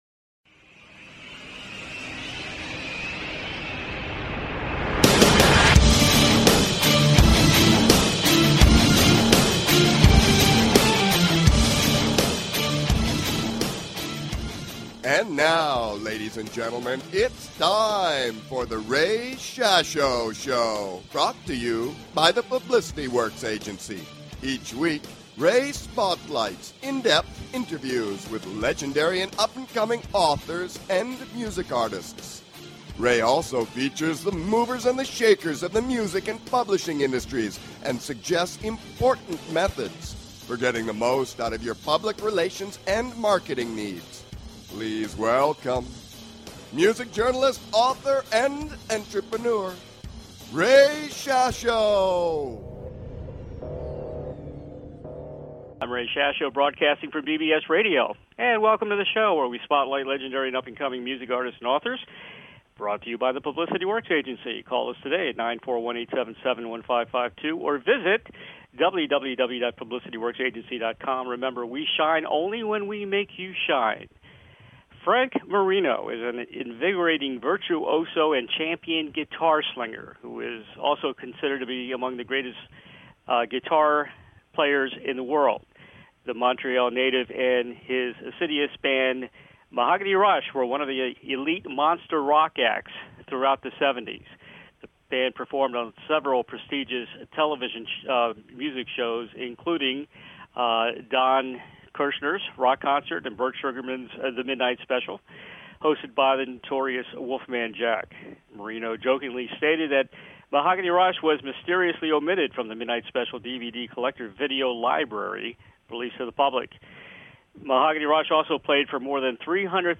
Guest, Frank Marino